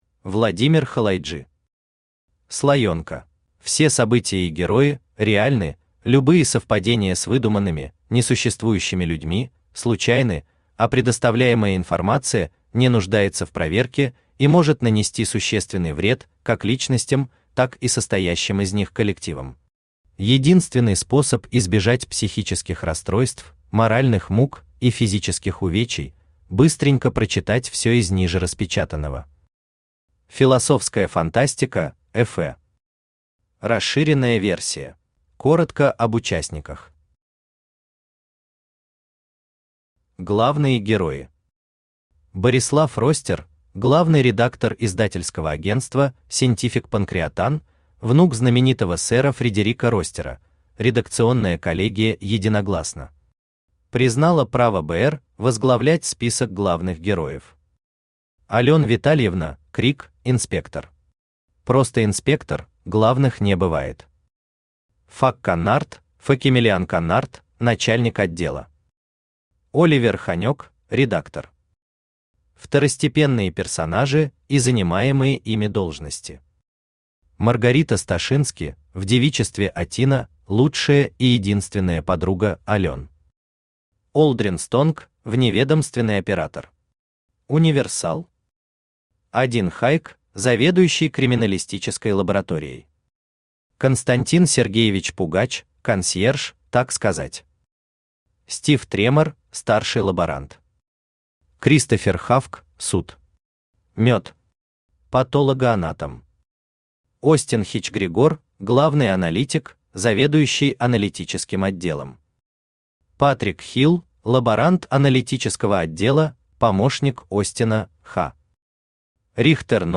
Аудиокнига Слоёнка | Библиотека аудиокниг
Aудиокнига Слоёнка Автор Владимир Халайджи Читает аудиокнигу Авточтец ЛитРес.